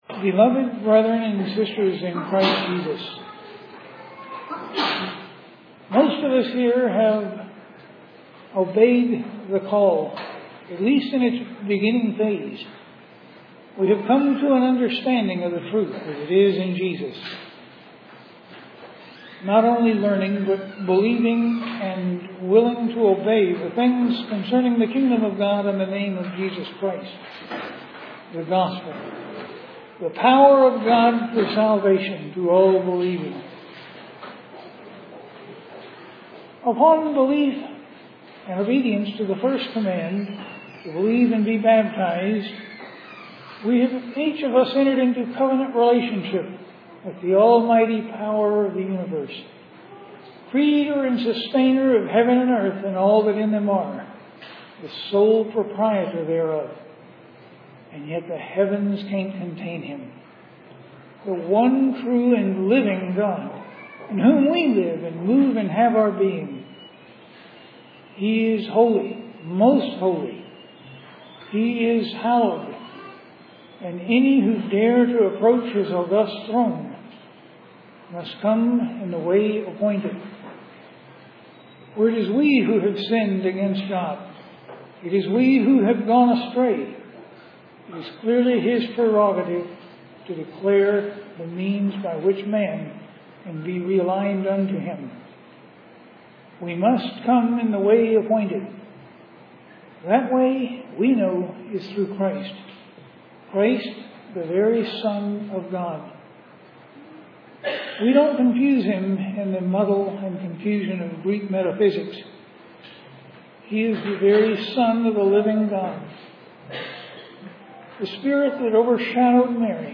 The Houston Ecclesia held a gathering in April 2014.